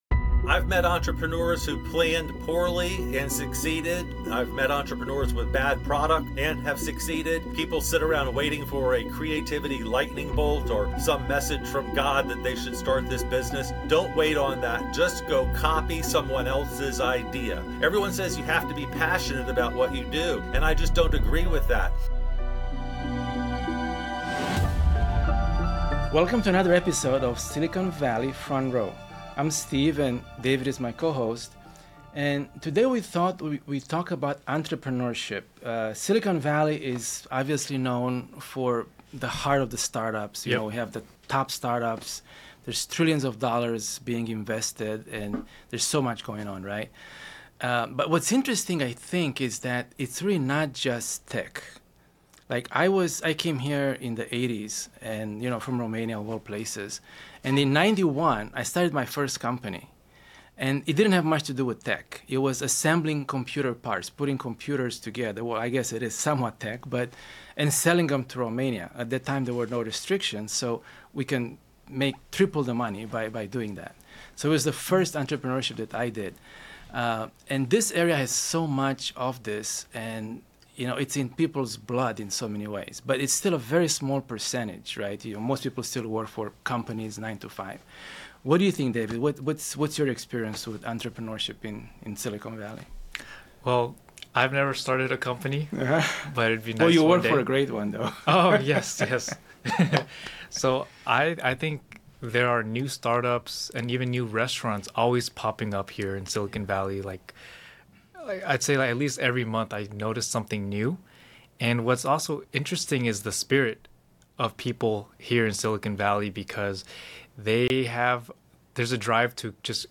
Starting a business with $5K - Interview with a serial entrepreneur